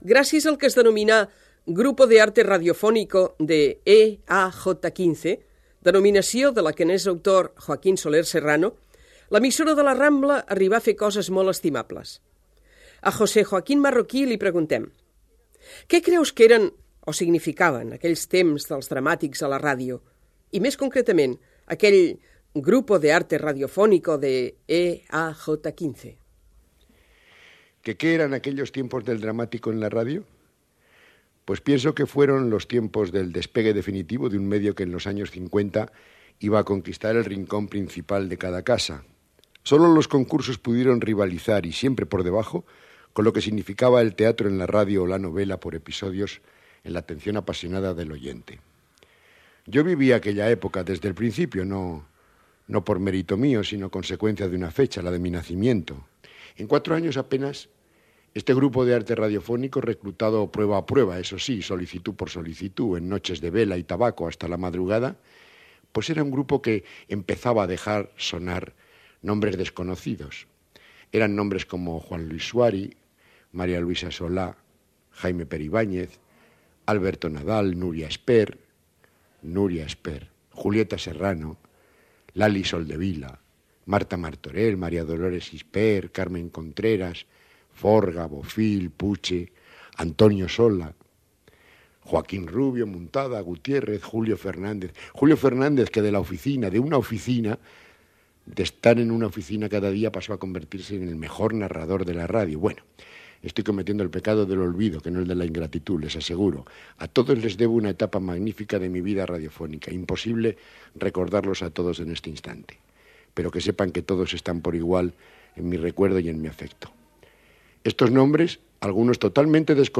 Divulgació
FM